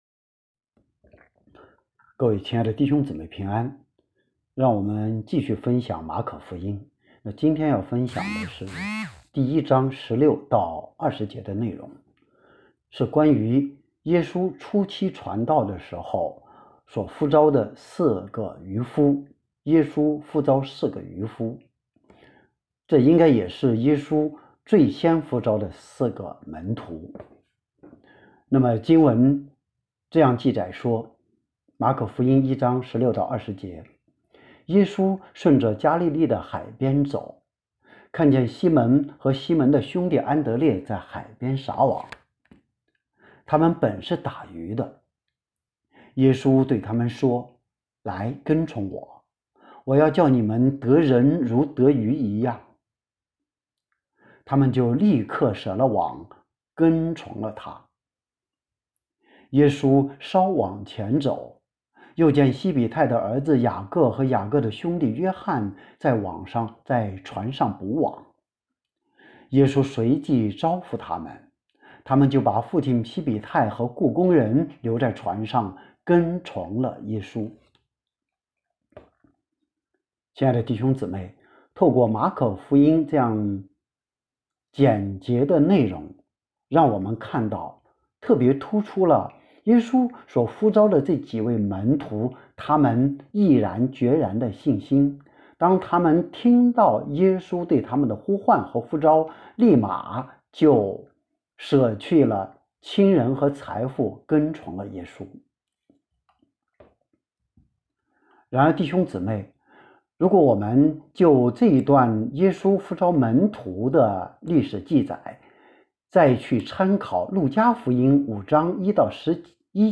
耶稣呼召四个渔夫》 证道